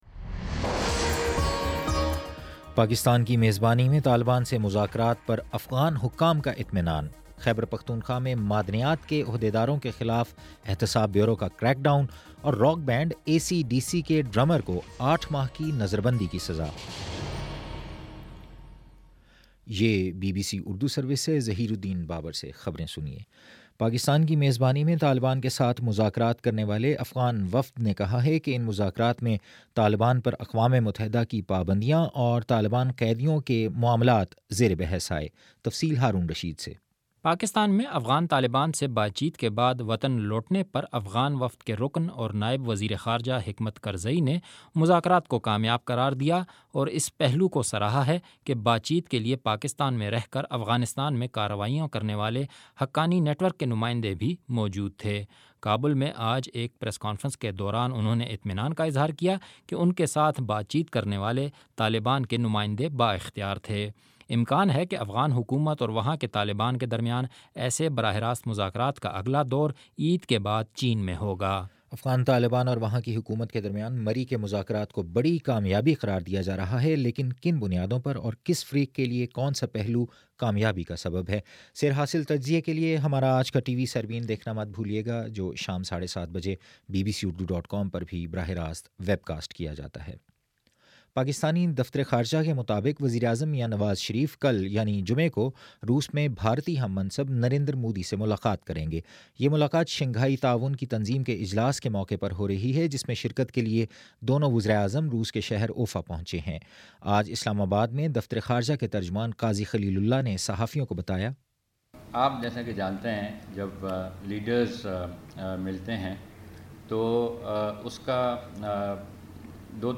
جولائی 9: شام چھ بجے کا نیوز بُلیٹن